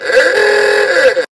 Goofy Ahh Sound Effect Free Download